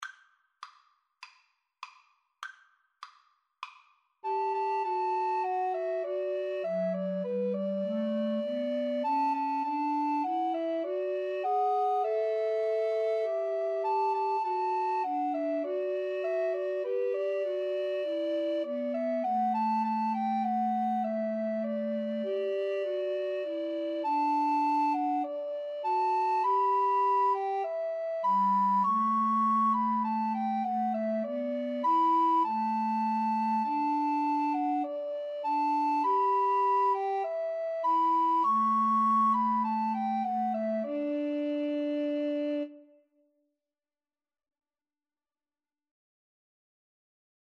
Alto RecorderTenor RecorderBass Recorder
4/4 (View more 4/4 Music)